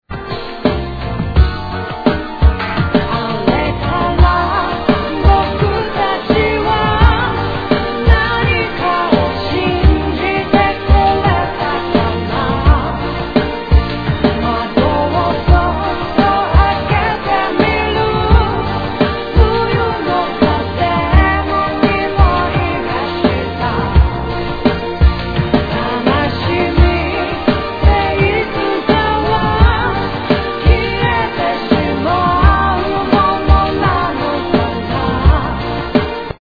Tag       Japan R&B